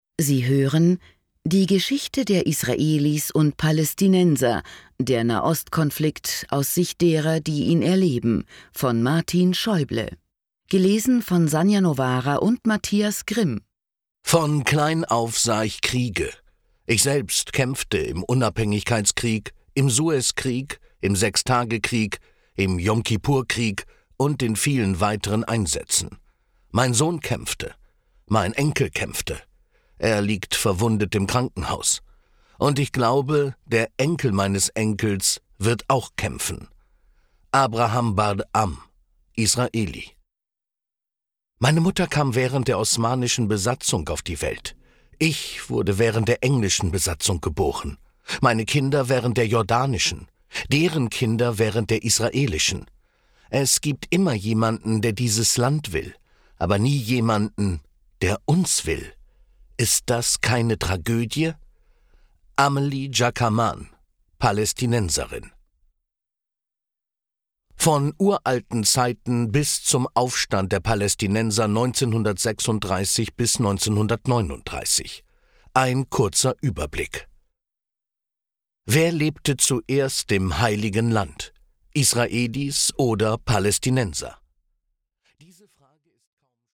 Hörbuch:
Hoerprobe-Audiobook-Die-Geschichte-der-Israelis-und-Palaestinenser.mp3